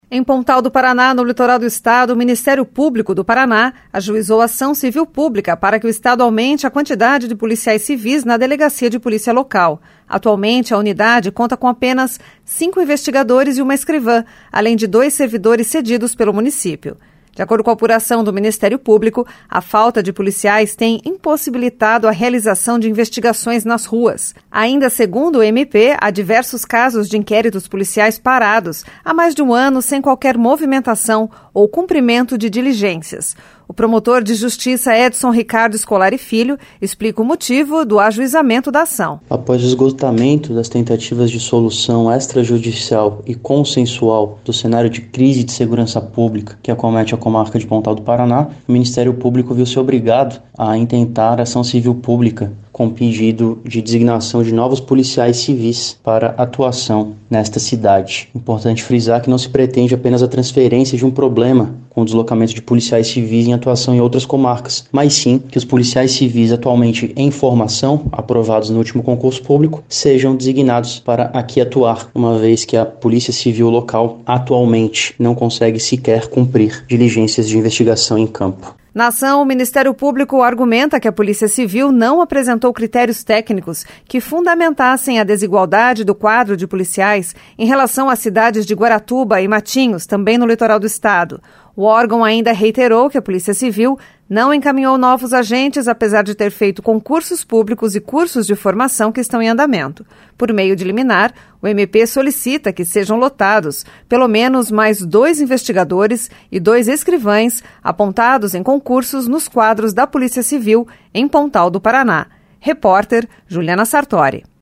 O promotor de Justiça Edson Ricardo Scolari Filho explica o motivo de ajuizamento da ação.